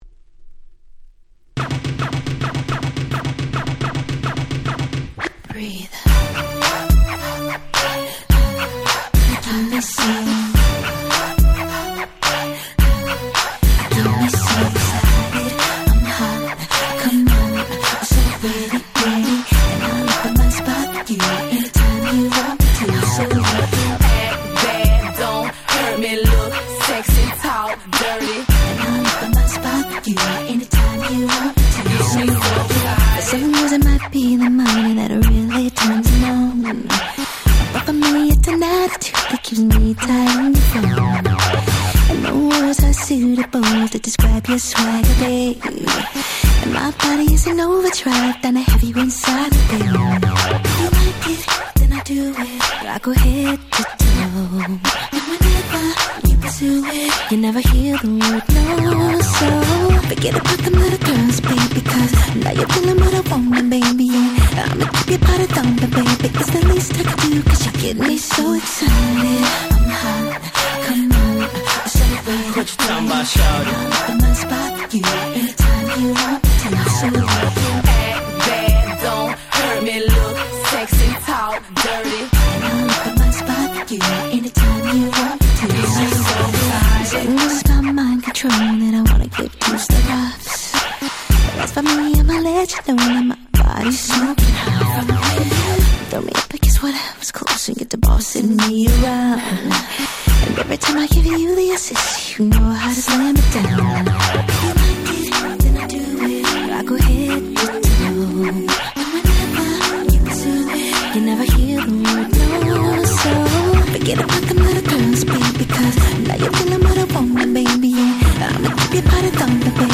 06' Smash Hit R&B !!
でもブリッジは美メロで歌物好きもしっかり昇天出来るさすがの仕上がり！！
オールドスクール